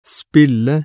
Hvis plosiven uttales etter en blir ikke plosiven aspirert, som i f.eks. spille:
so_spille_01.mp3